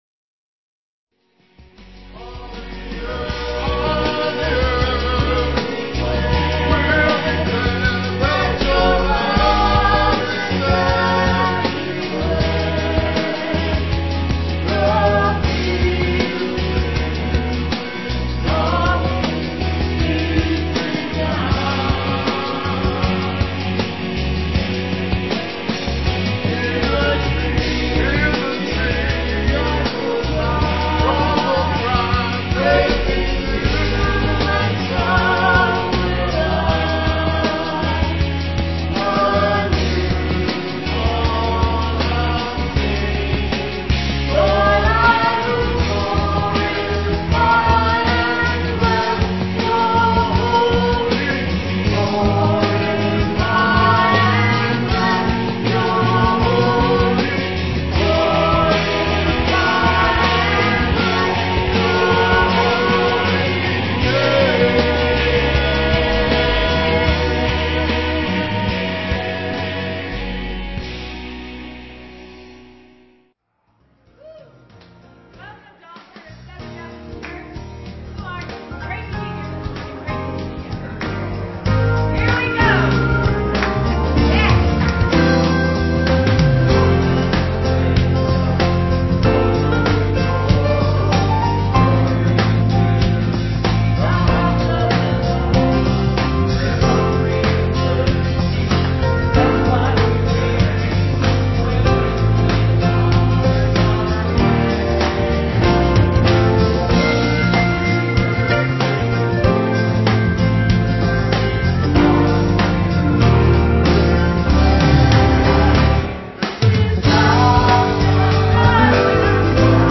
Piano and organ offertory
Sung by the ABC Harmony.